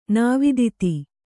♪ nāviditi